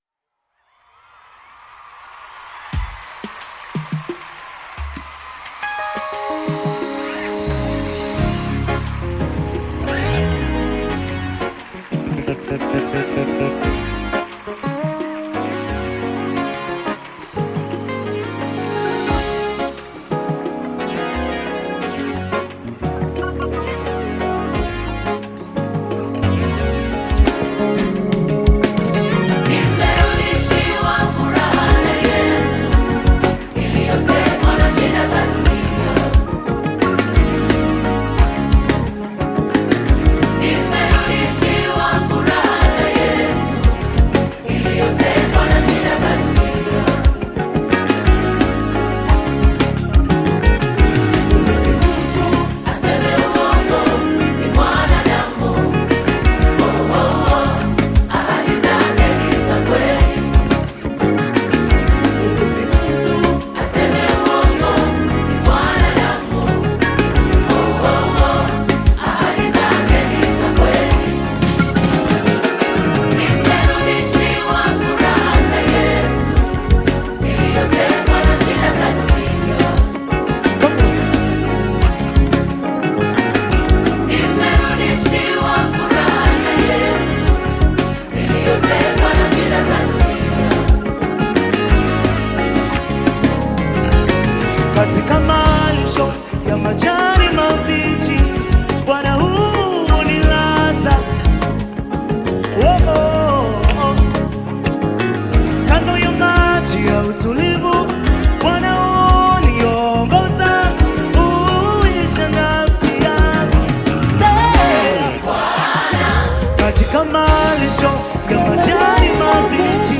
Praise music
Praise Gospel music track